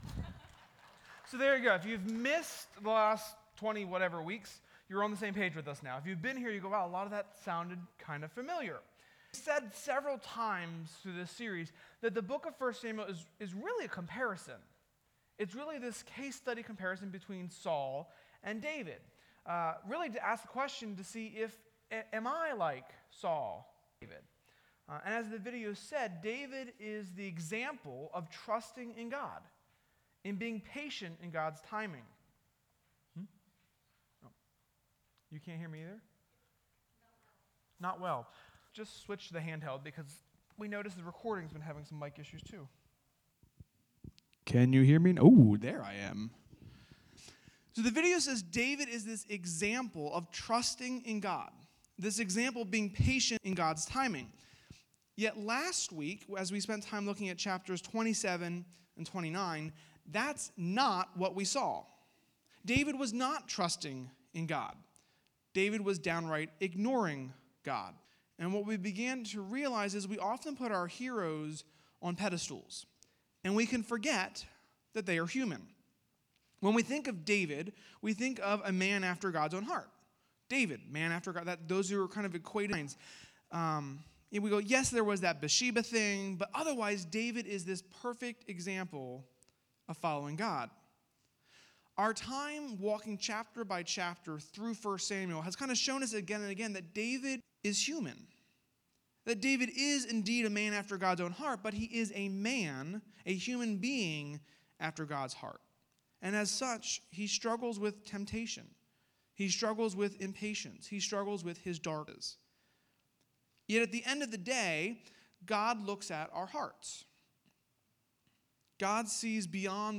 (The video below was shown as an introduction to the sermon/recap of 1 Samuel and is alluded to several times throughout the sermon)